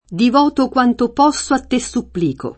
supplicare v.; supplico [S2ppliko], -chi — un es. poet. di pn. piana: Divoto quanto posso a te supplico [